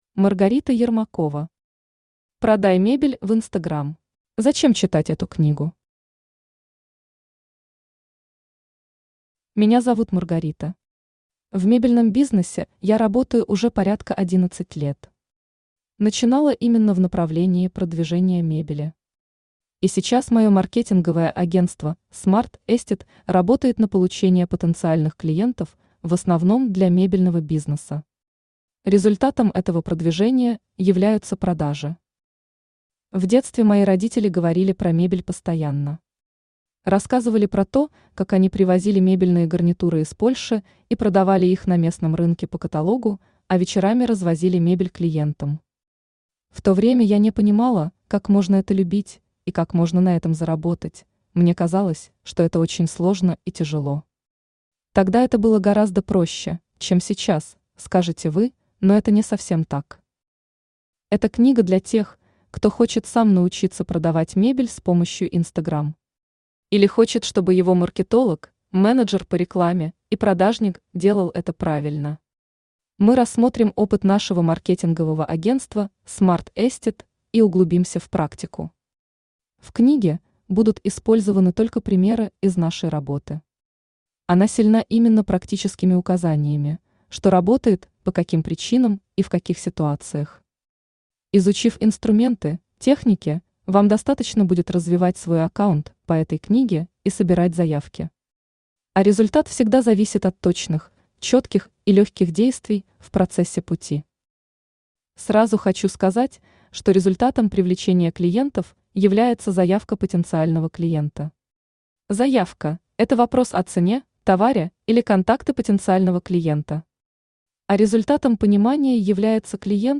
Аудиокнига Продай мебель в Instagram | Библиотека аудиокниг
Aудиокнига Продай мебель в Instagram Автор Маргарита Сергеевна Ермакова Читает аудиокнигу Авточтец ЛитРес.